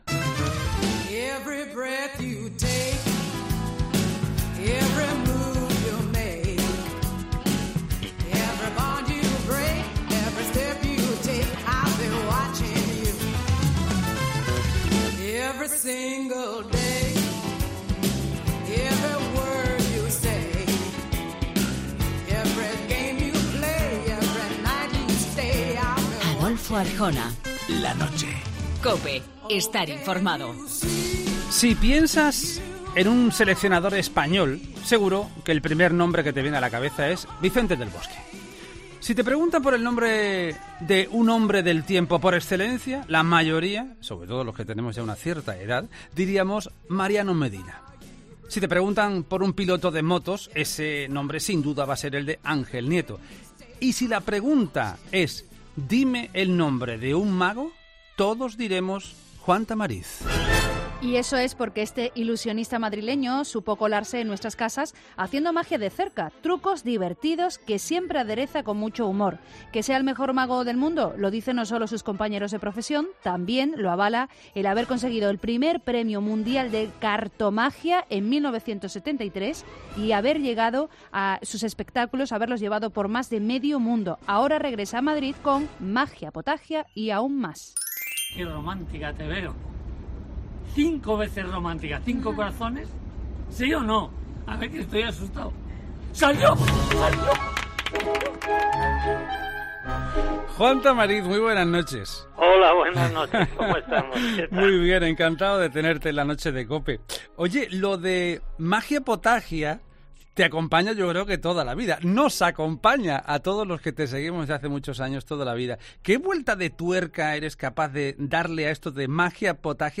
Entrevista a Juan Tamariz en 'La Noche'